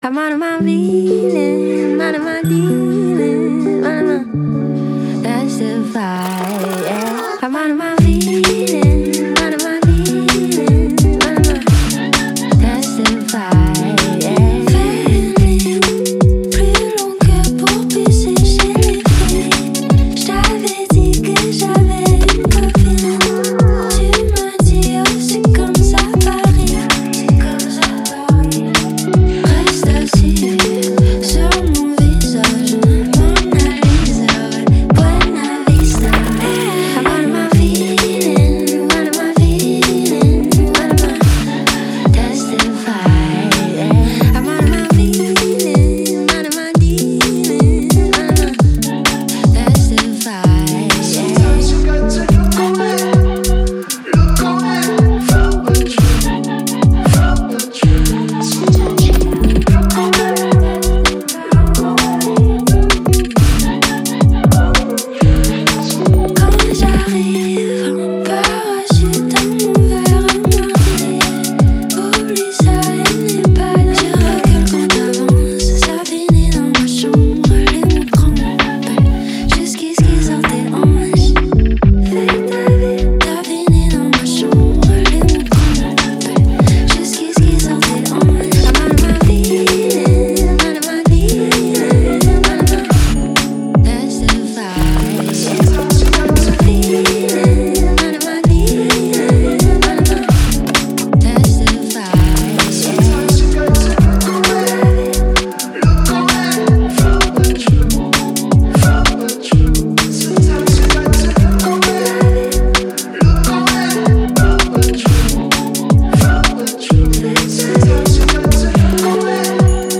UK garage